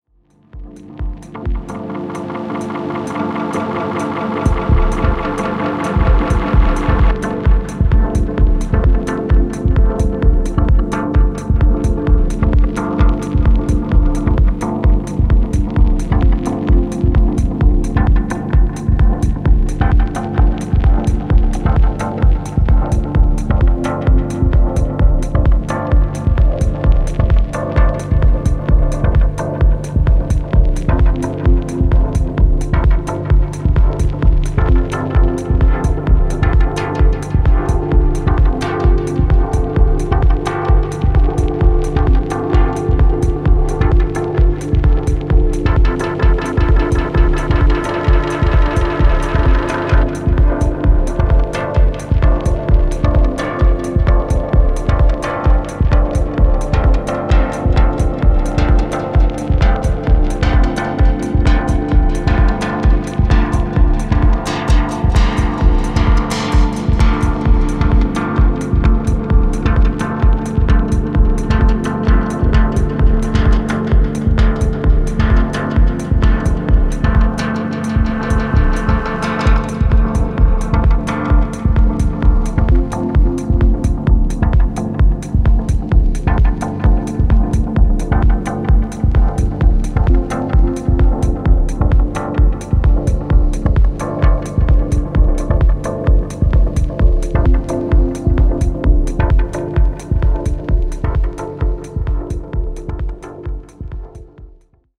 Techno Dub